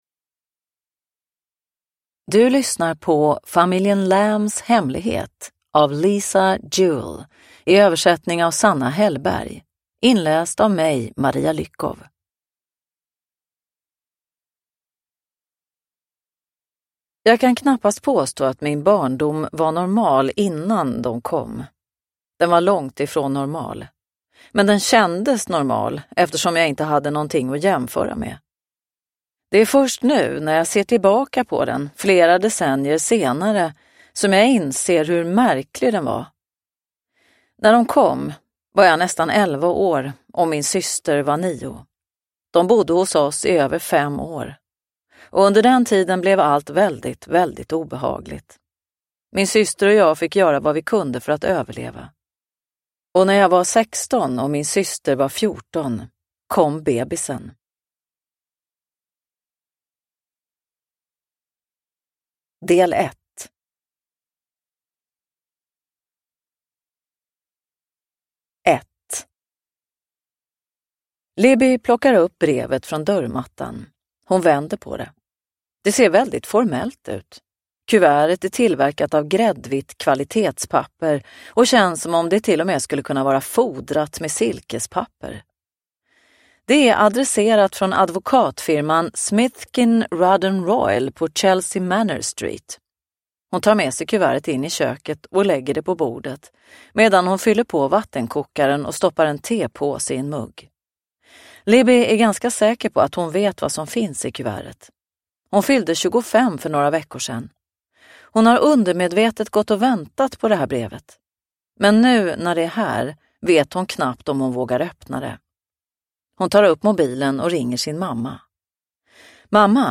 Familjen Lambs hemlighet – Ljudbok – Laddas ner